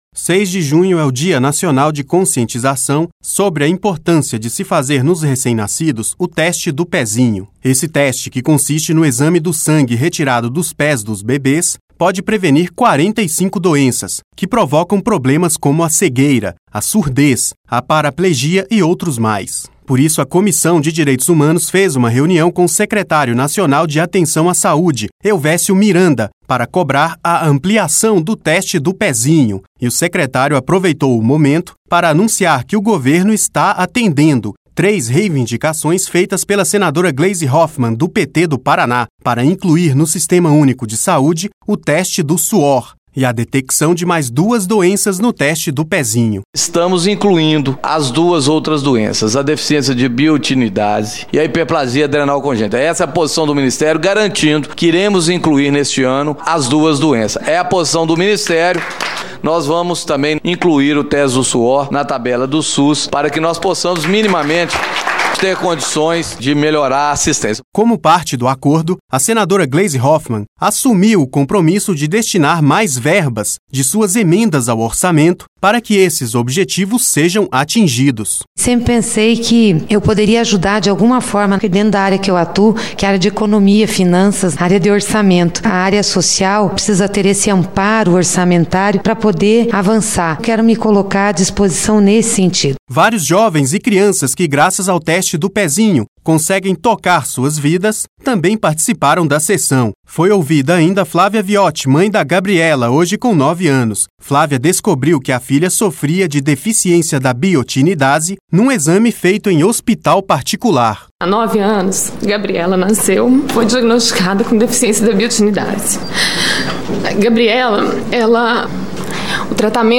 É a posição do Ministério (palmas).